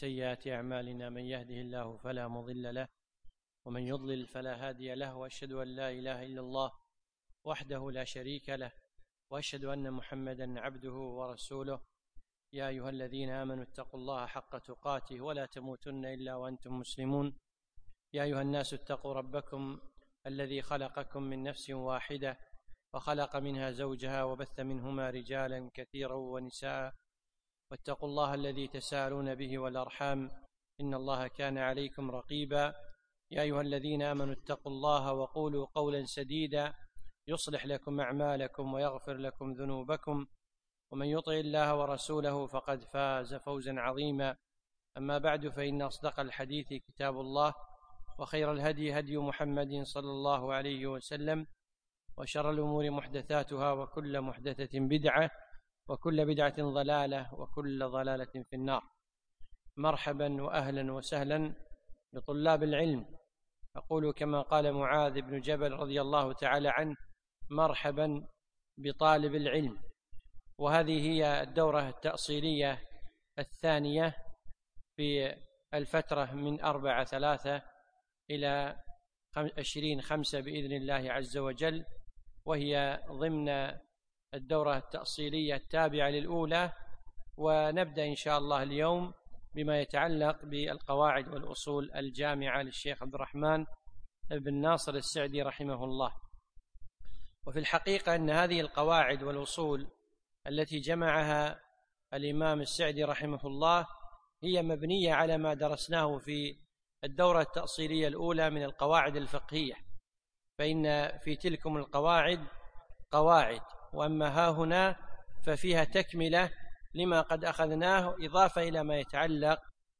يوم السبت 5 جمادى ثاني 1438 الموافق 4 3 2017 في مسجد عائشة المحري المسايل